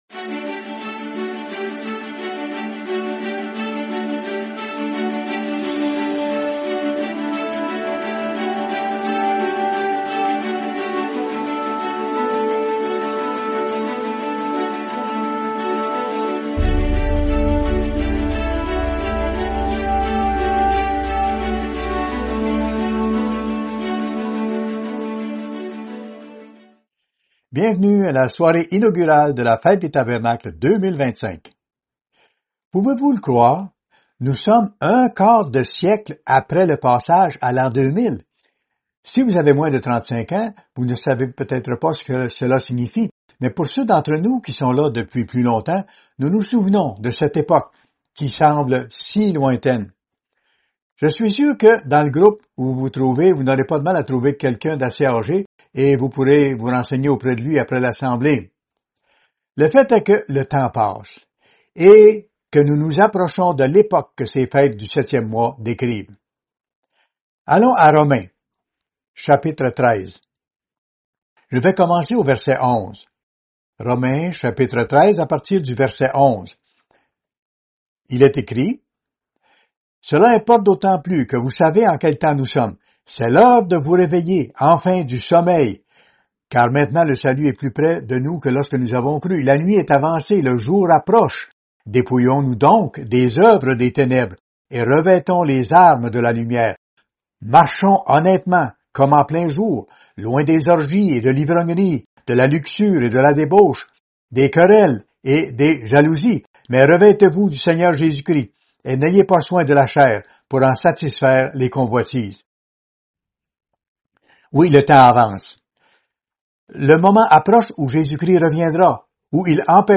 Soirée inaugurale de la Fête des Tabernacles 2025